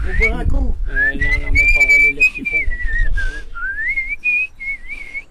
Sifflement pour faire boire les boeufs
Chaize-le-Vicomte (La)